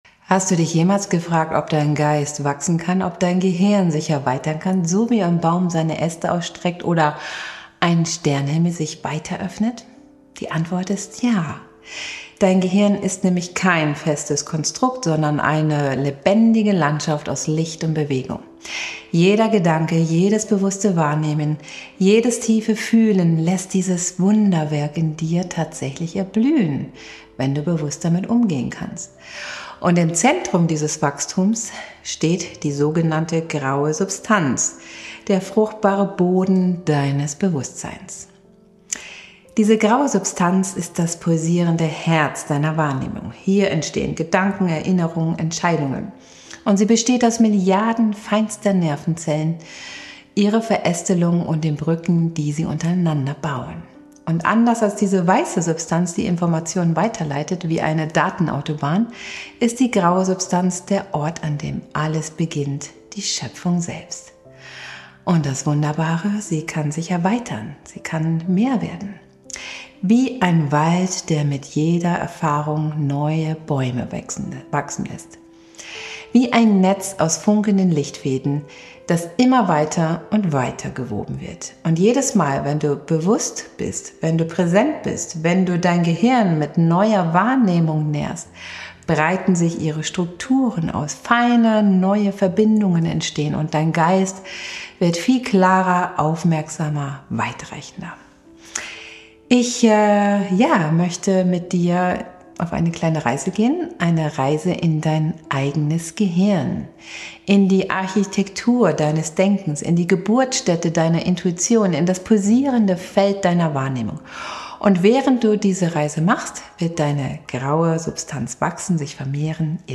Diese Episode führt dich durch eine geführte Meditation, die deine graue Substanz aktiviert, neuronale Verbindungen stärkt und dein Bewusstsein erweitert, sodass du geistige Klarheit, Fokus und innere Balance gewinnst.